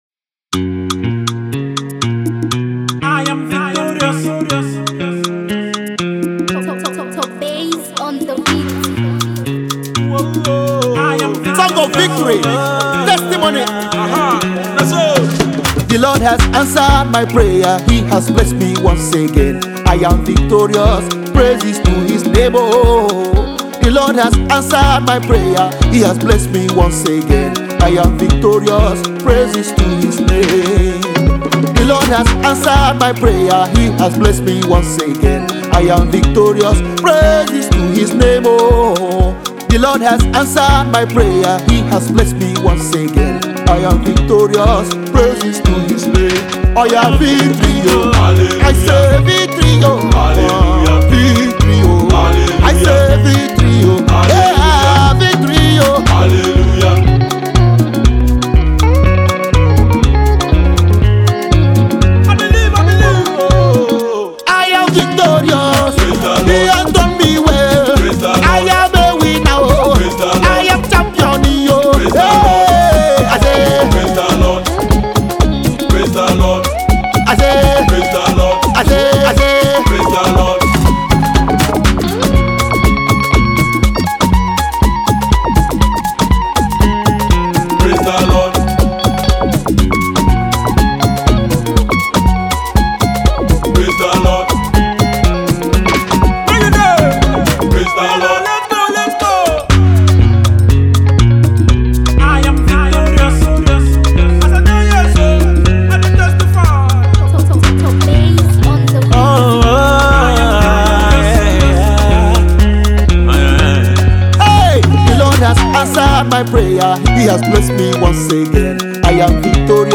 Popularly UK-Based Nigeria gospel music minister